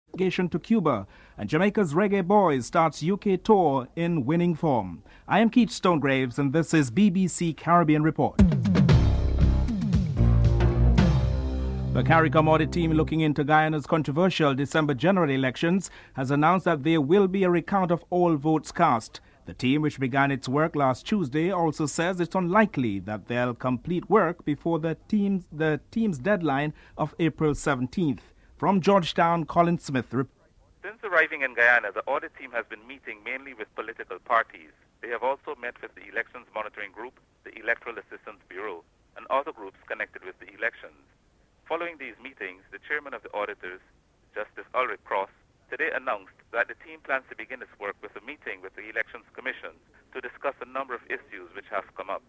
1. Headlines
4. St. Kitts/Nevis Prime Minister Denzil Douglas leads a delegation to Cuba.